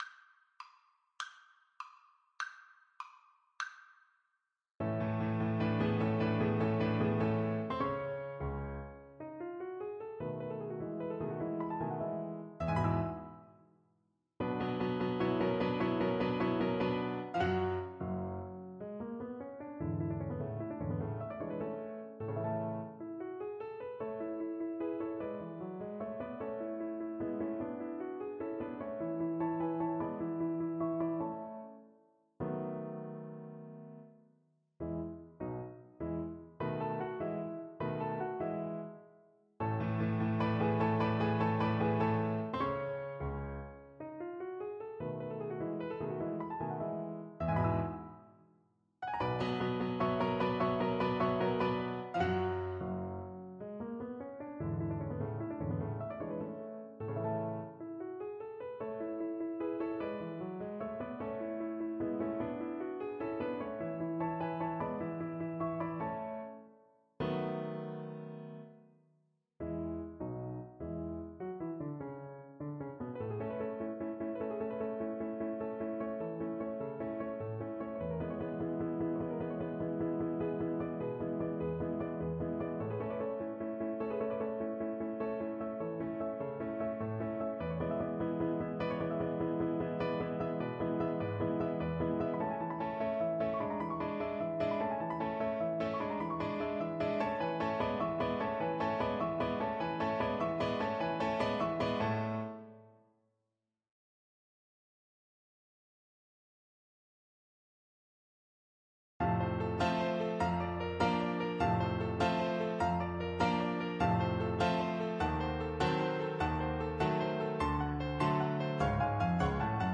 Violin version
6/8 (View more 6/8 Music)
Allegro vivace . = c. 100 (View more music marked Allegro)
Classical (View more Classical Violin Music)